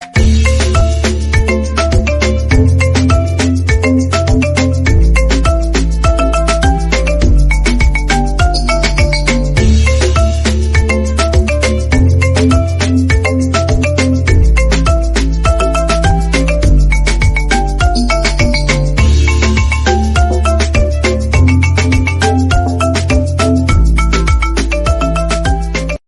Catégorie Marimba Remix